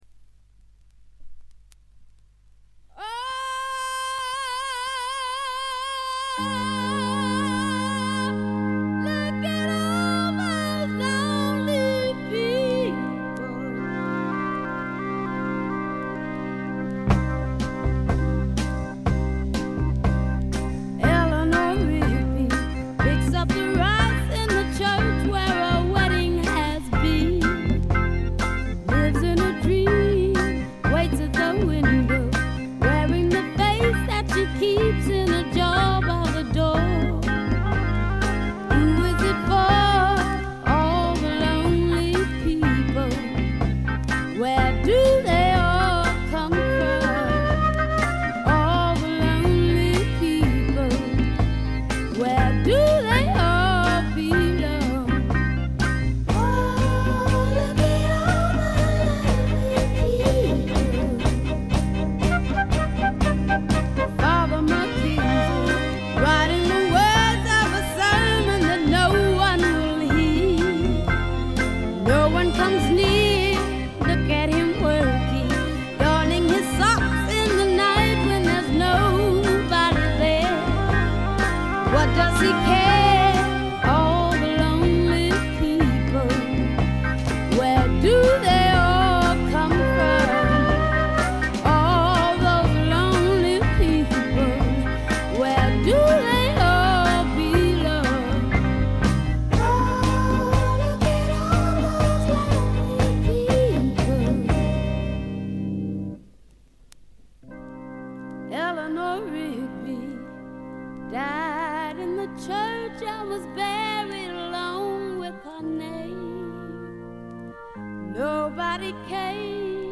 全体にバックグラウンドノイズ、チリプチ多め。
試聴曲は現品からの取り込み音源です。